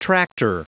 Prononciation du mot tractor en anglais (fichier audio)
Prononciation du mot : tractor